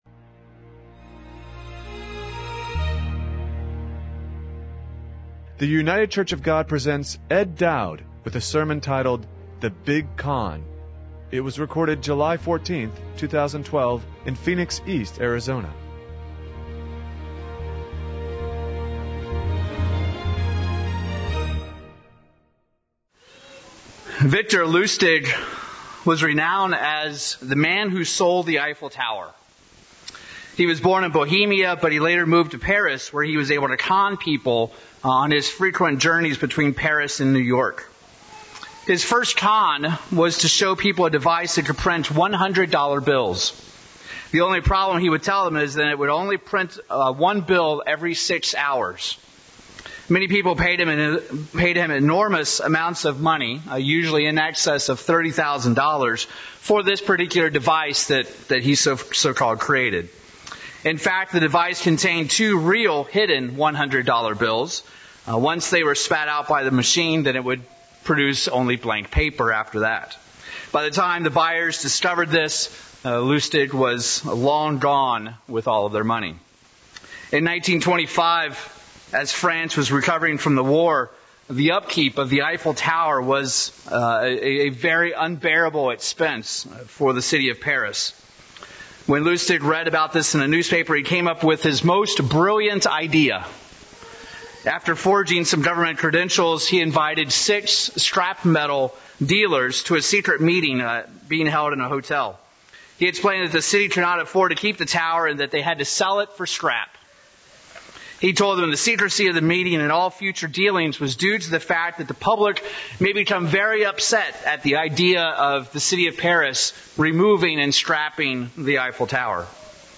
In the sermon I'd like to explore Satan's tactics because even now today he continues his master con, his biggest con of all time, which is the fact that man does not need God.